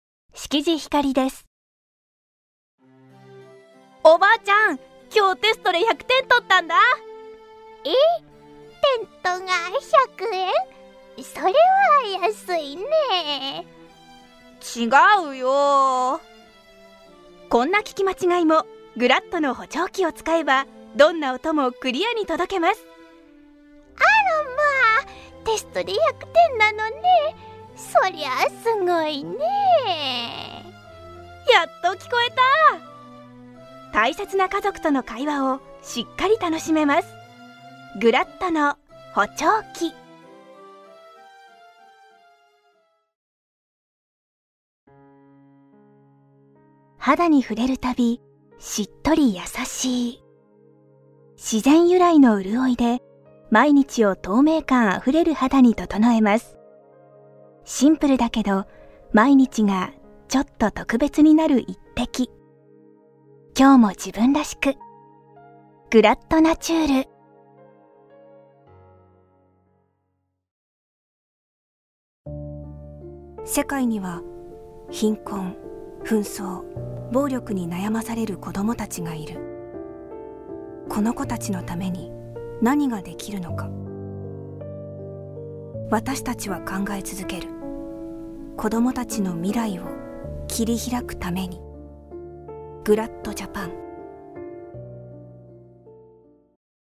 ボイスサンプル
• 音域：高～中音
• 声の特徴：元気、明るい、キャラボイス
• CM